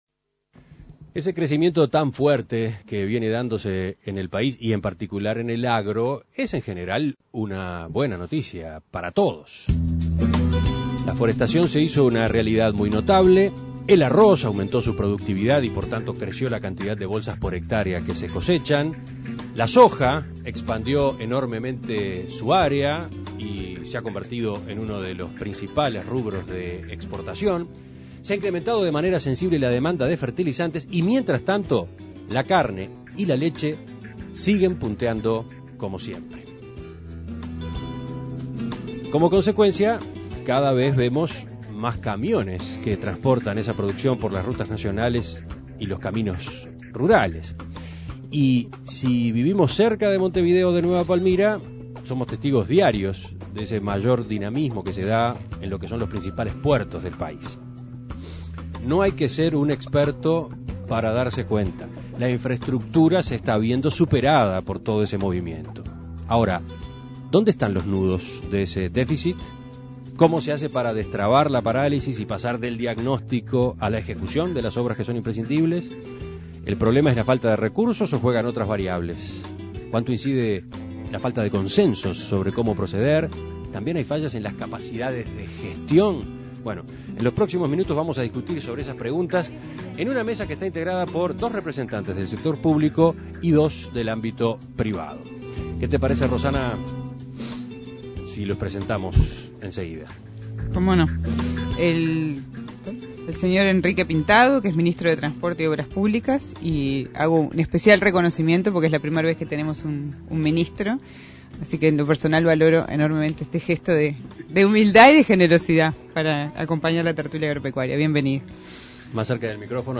Uruguay está en pleno crecimiento, pero ¿en qué condiciones están los canales por donde se transporta nuestra producción? La Tertulia Agropecuaria debatió sobre la infraestructura necesaria para un país en desarrollo.